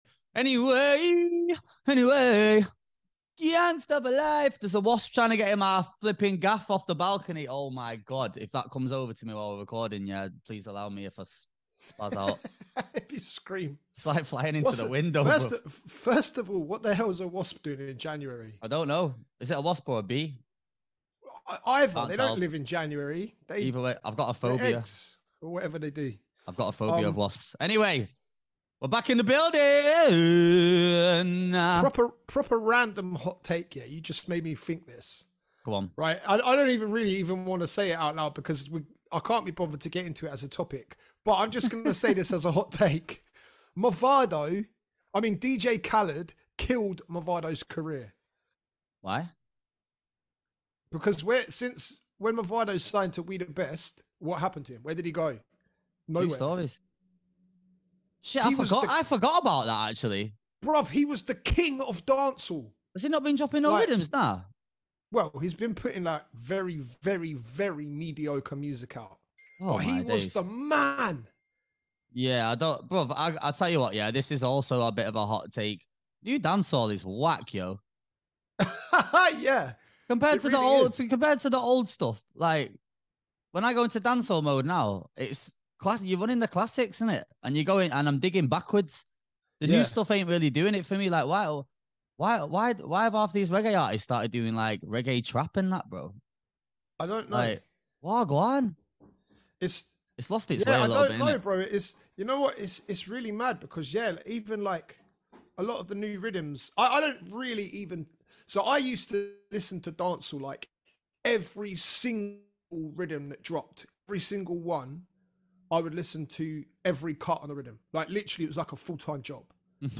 two DJs from the UK & we've decided that there needs to be an outlet for various topics relating to the DJ world and all things surrounding it…